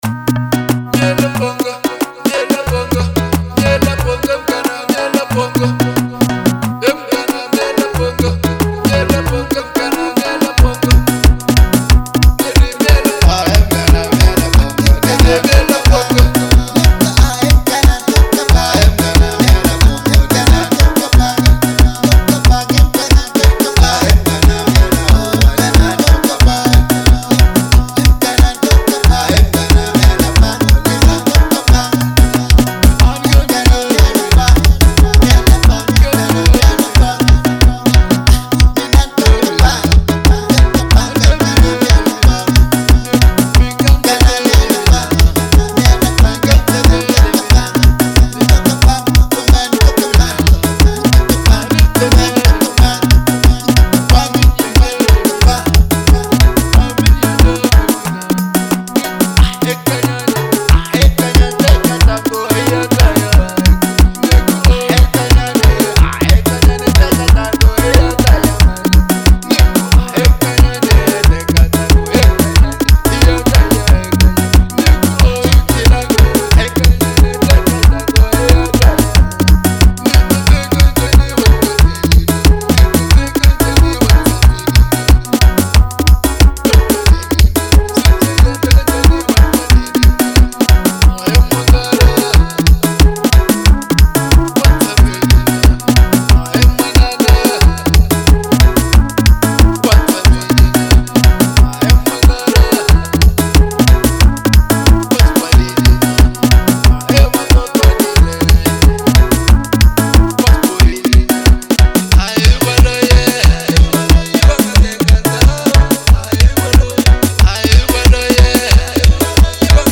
03:46 Genre : Xitsonga Size